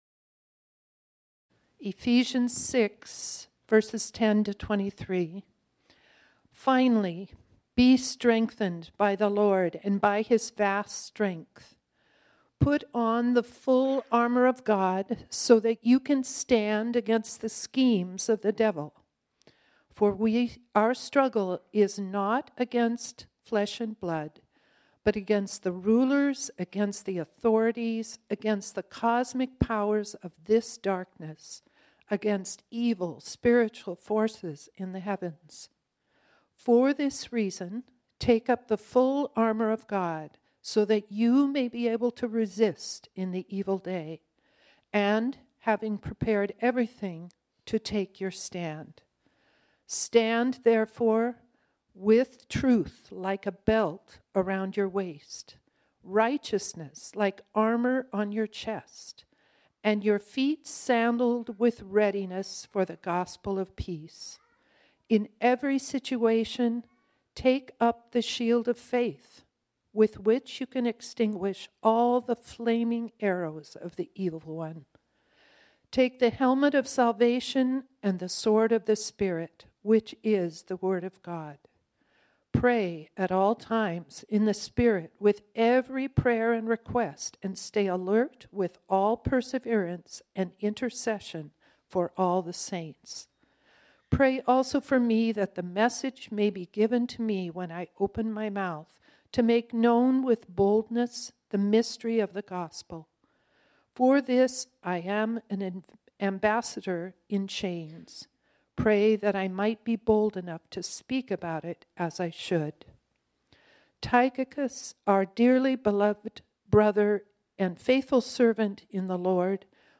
This sermon was originally preached on Sunday, November 26, 2023.